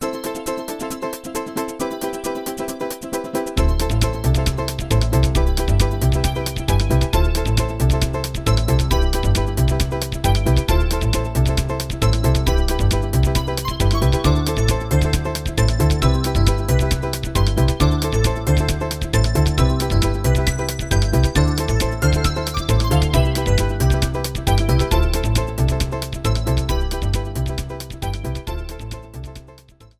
Shortened, fadeout